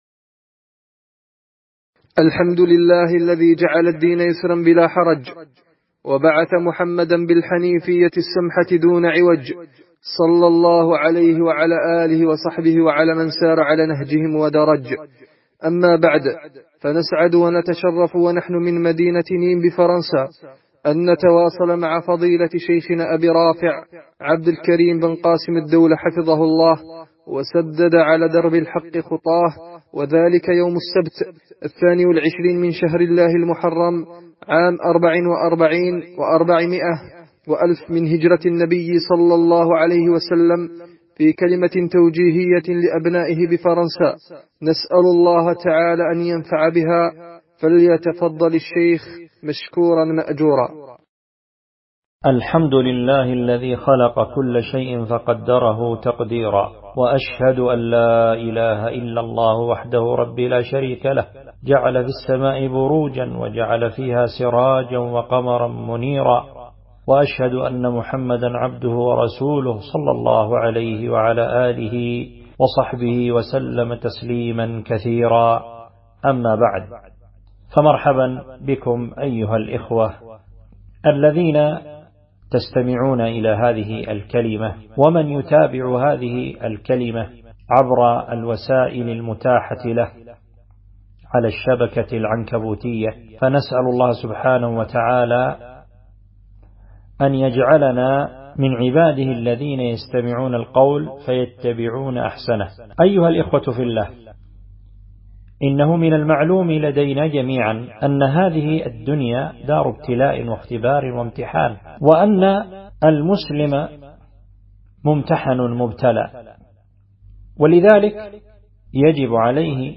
كلمة توجيهية إلى الأخوة في مدينة نيم بفرنسا